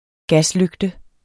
Udtale [ ˈgasˌløgdə ]